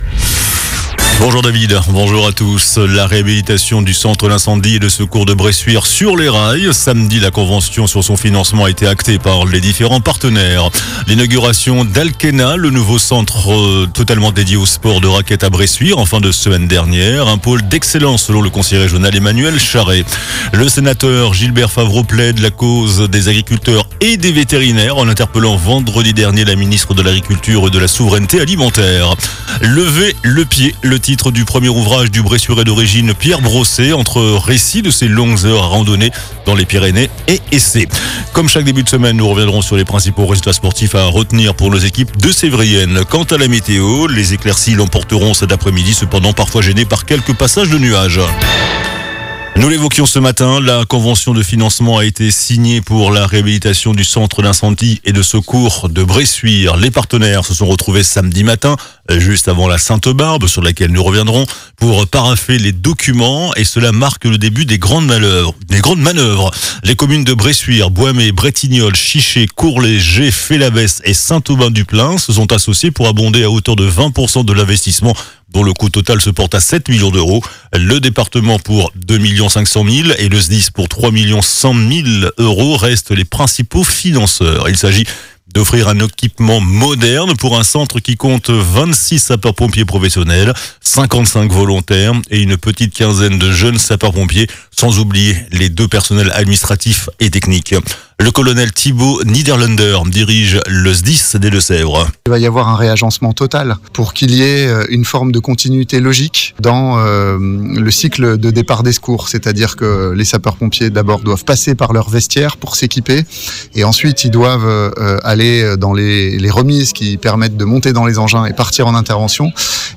JOURNAL DU LUNDI 15 DECEMBRE ( MIDI )